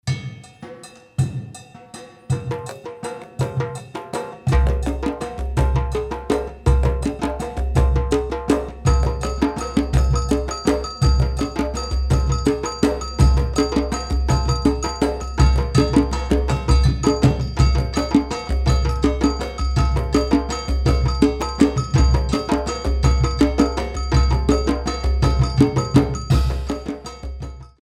twelve beats